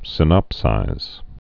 (sĭ-nŏpsīz)